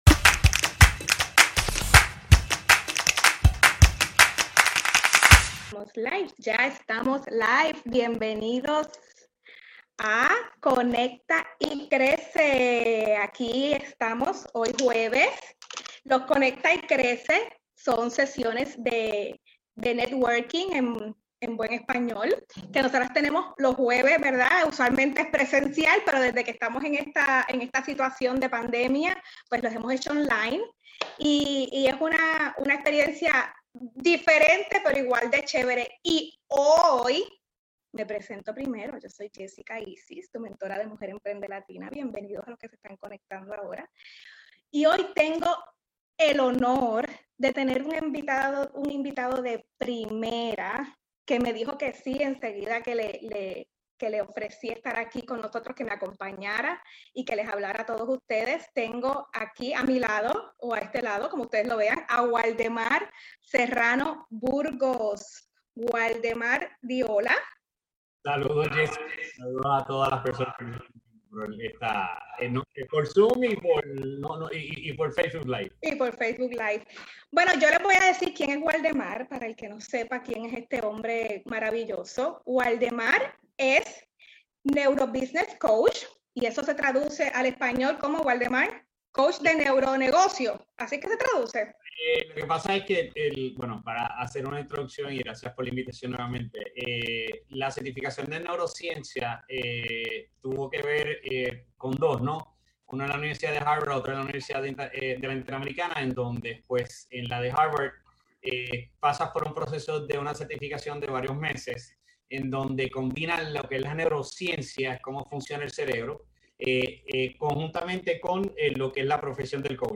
entrevistado en Mujer Emprende Latina